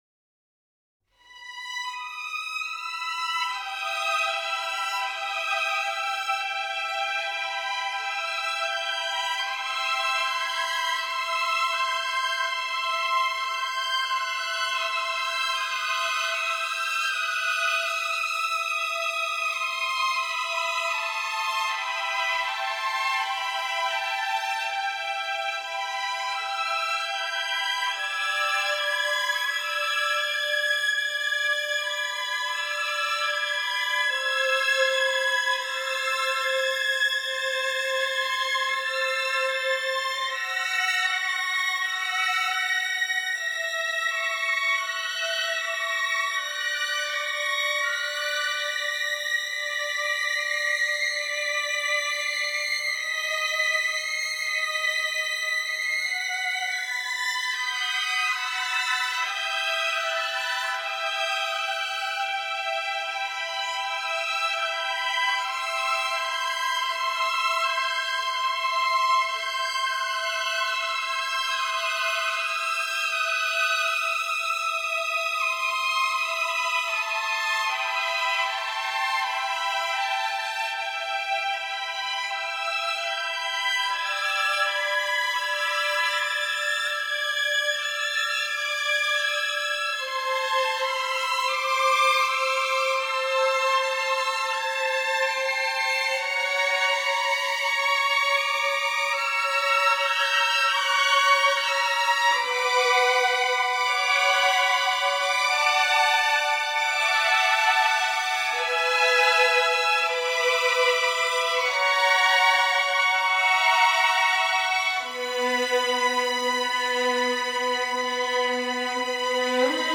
in a small chamber configuration and full string orchestra